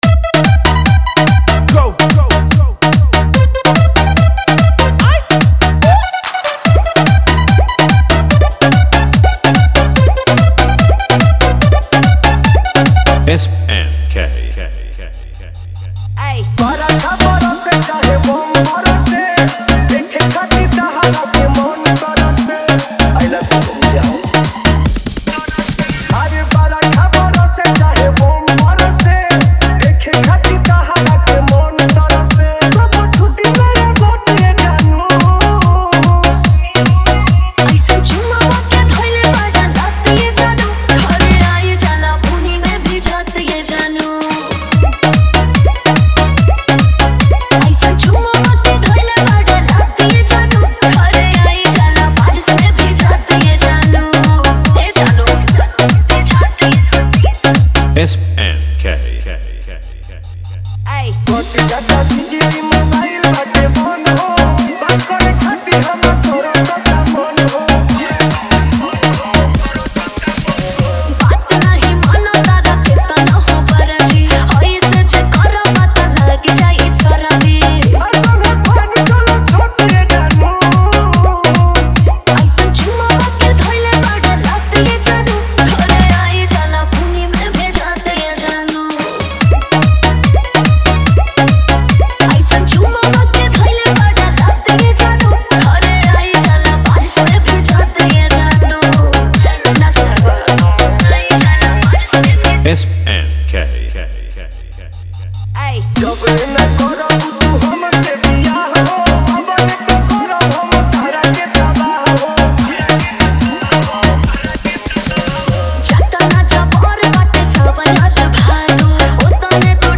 न्यू भोजपुरी DJ रीमिक्स सॉन्ग